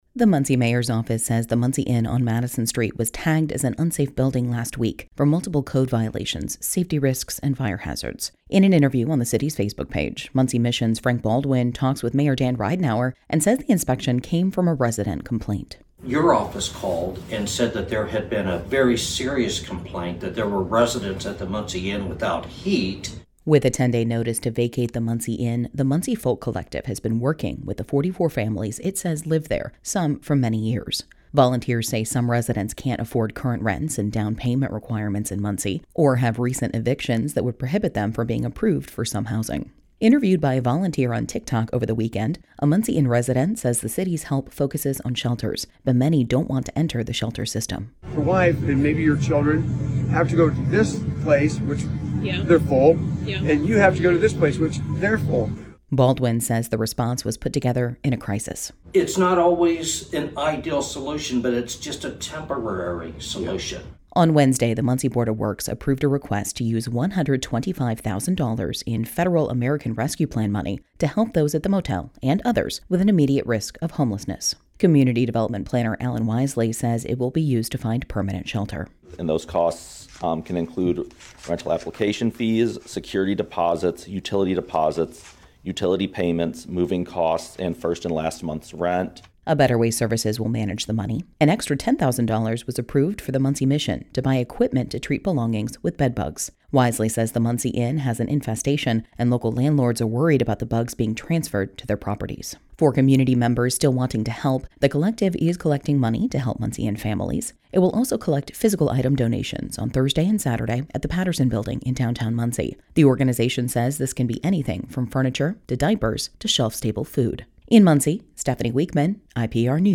Interviewed by a volunteer on TikTok over the weekend, a Muncie Inn resident says the city’s help focuses on shelters, but many don’t want to enter the shelter system.